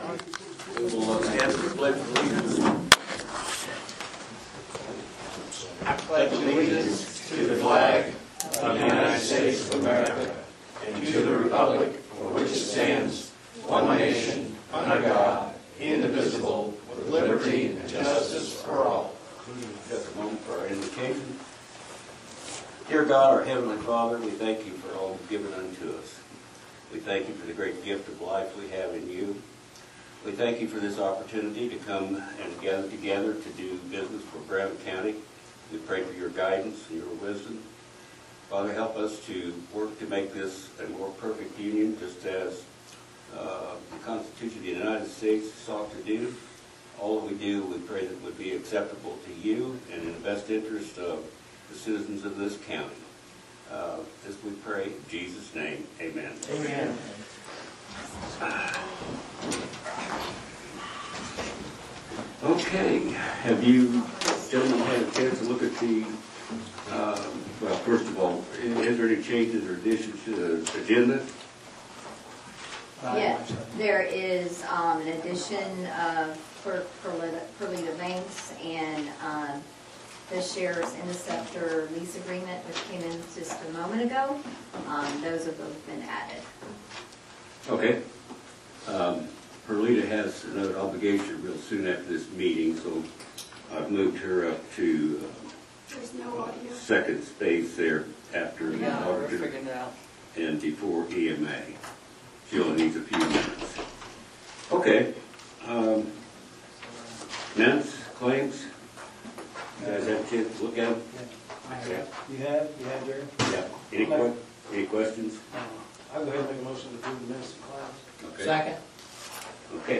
Exec Meetings are held on confidential issues that are specified in statutes.